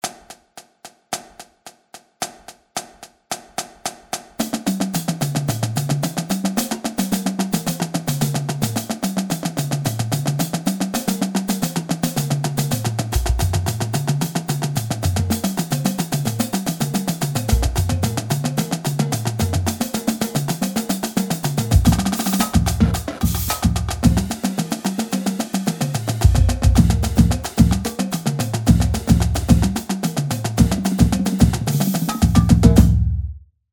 Stick Control 2 - 110.mp3